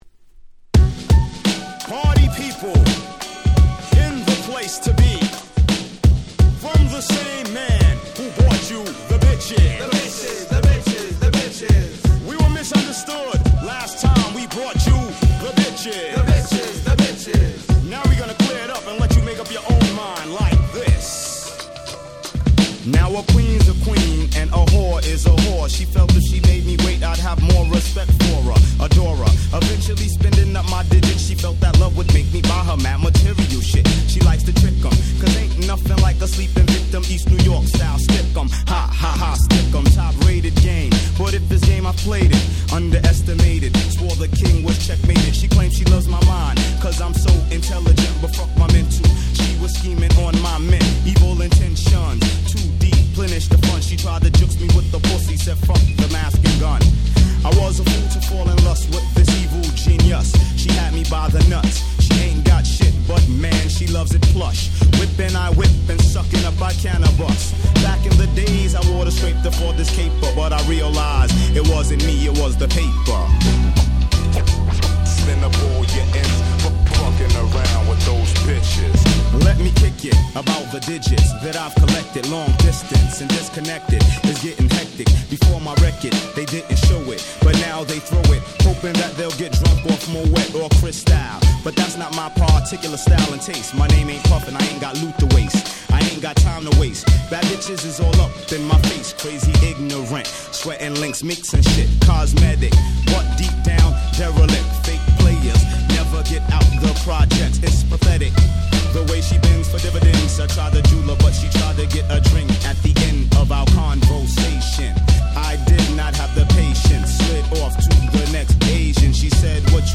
90's Hip Hop Classics !!